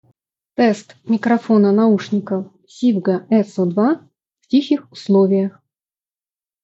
Качество передаваемого звука — высокого уровня, очень корректно работает шумоподавление. Звучание понятное и четкое.
В тихих условиях: